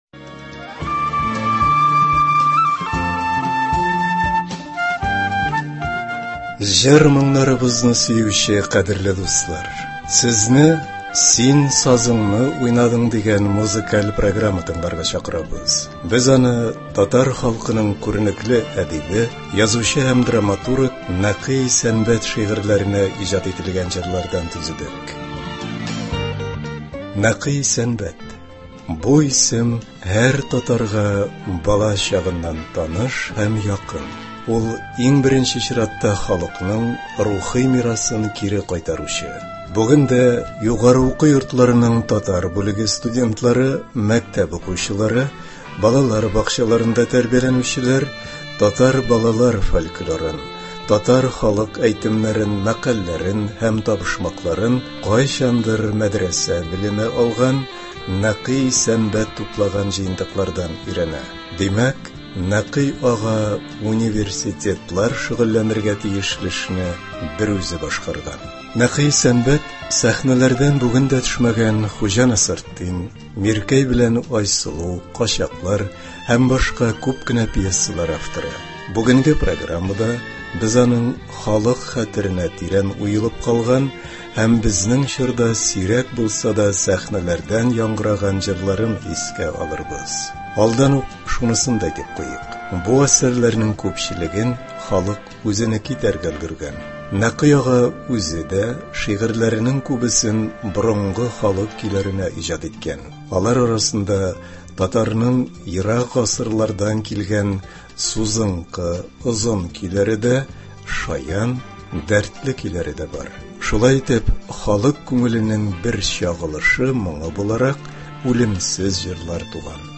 Концерт (23.03.22)